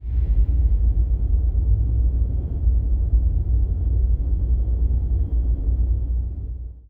evil-wind-2.wav